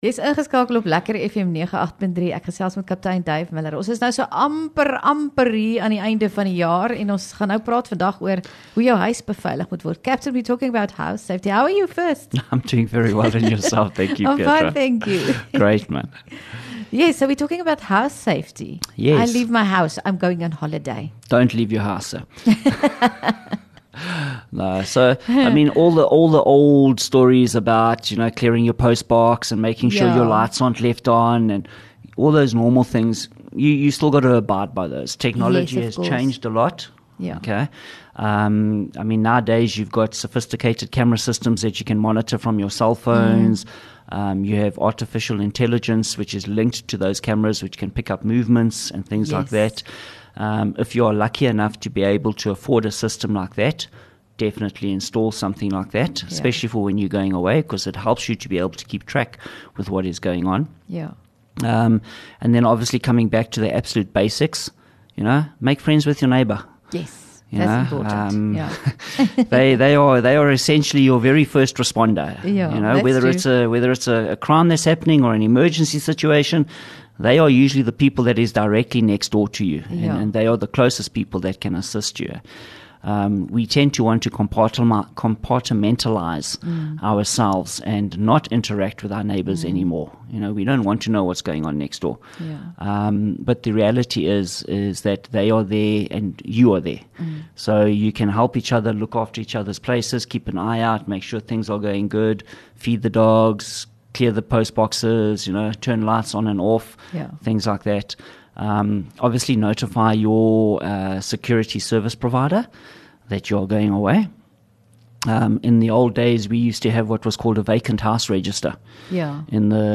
LEKKER FM | Onderhoude 19 Nov Misdaad Verslag